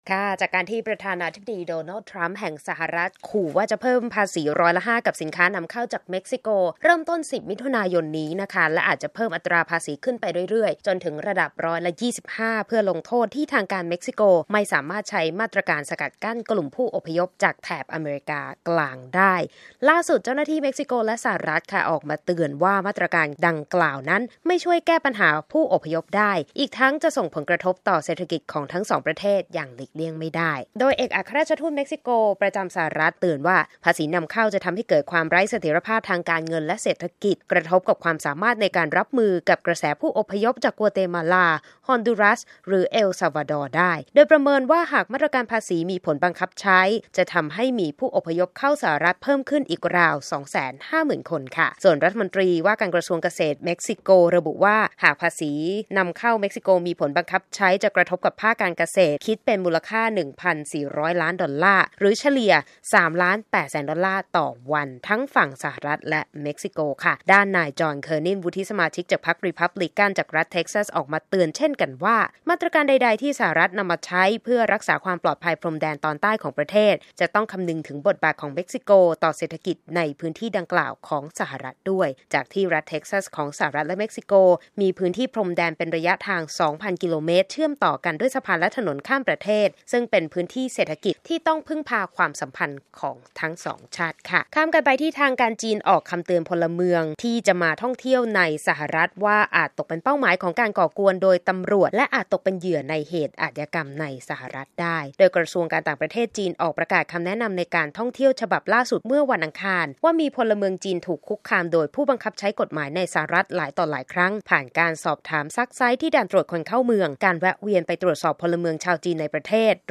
ข่าวธุรกิจ 5 มิ.ย. 2562